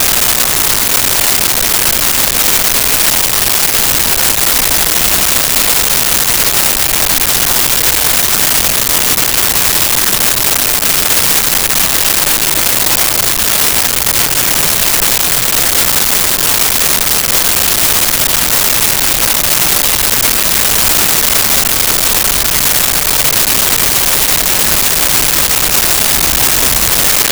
Shopping Mall Interior
Shopping Mall Interior.wav